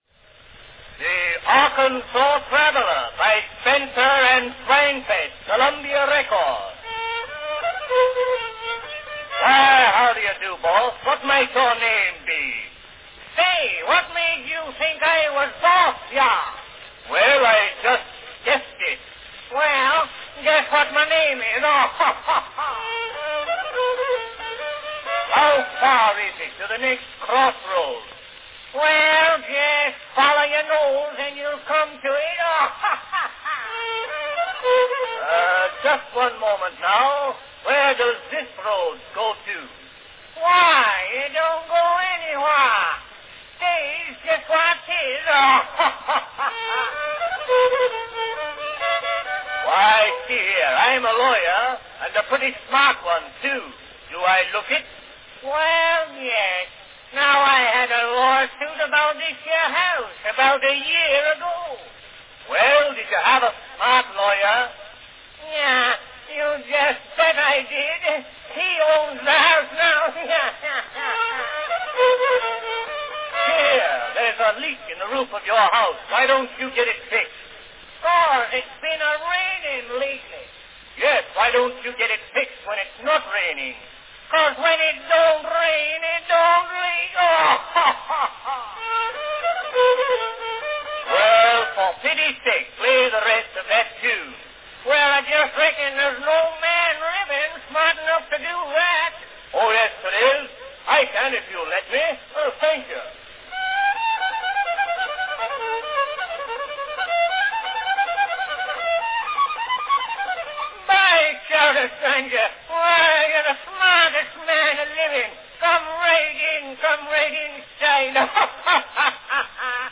A popular novelty record from 1902
Category Descriptive talk
These selections typically featured both talking (either in a comic, recitation, or dramatic setting) coupled with some manner of music, singing, or both.
plays the violin.